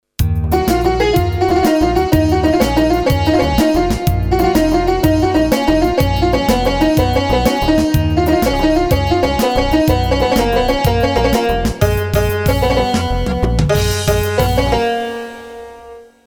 Asagida Dinlediginiz Sample Sesleri direk Orgla Calinip MP3 Olarak Kayit edilmistir
Tar